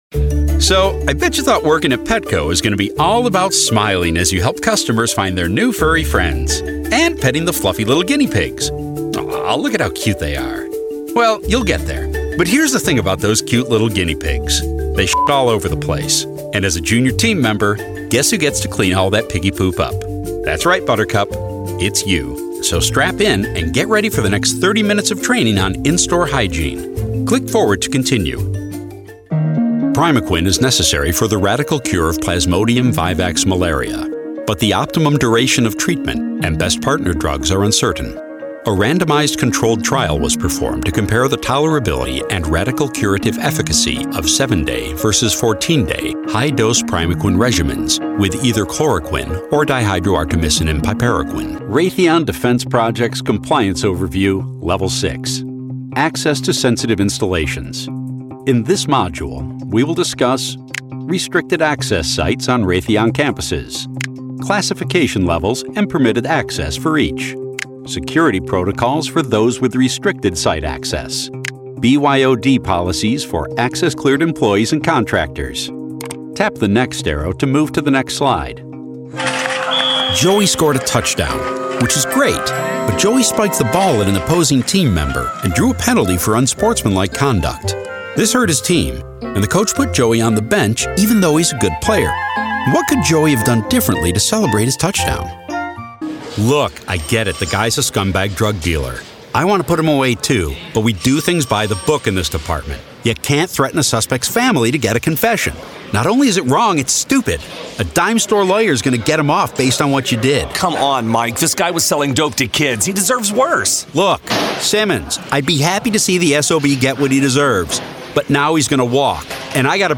Easy-going, Real, Conversational.
eLearning